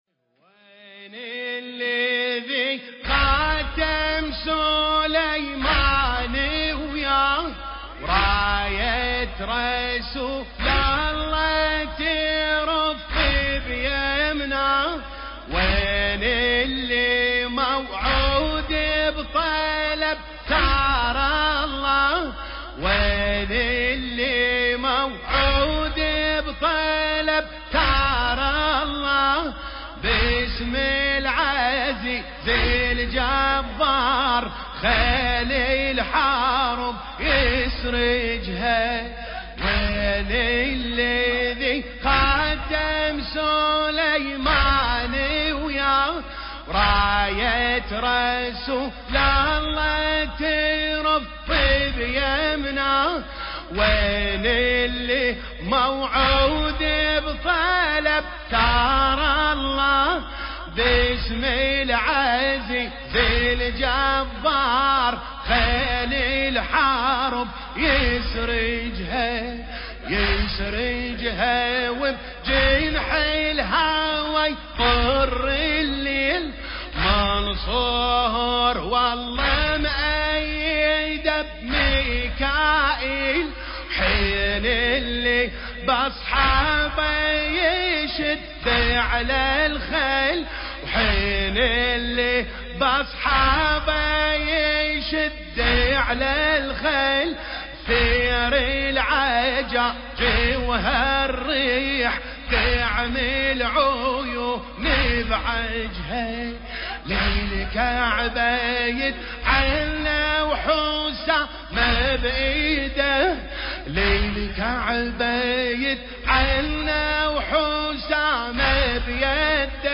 الحجم: ١.٨٨ MB الشاعر: ميرزا عادل أشكناني المكان: لندن التاريخ: الليالي الفاطمية – ١٤٤٠ للهجرة